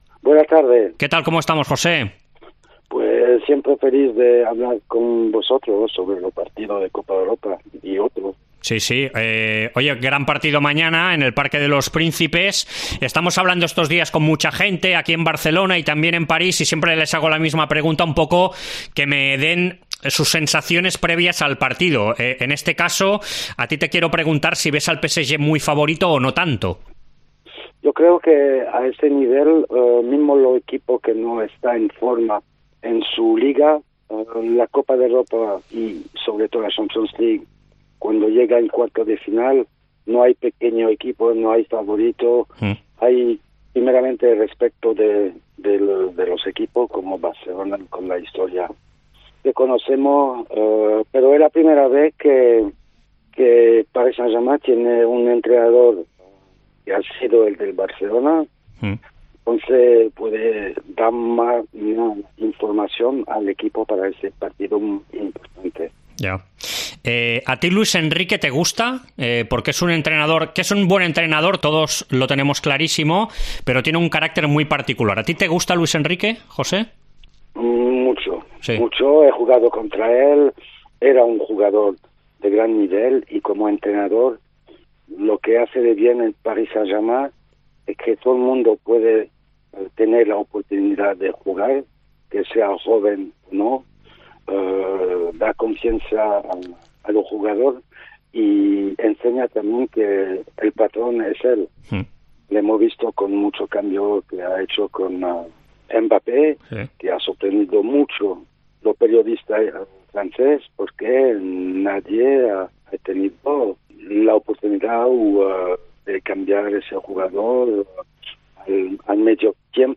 Entrevista Esports COPE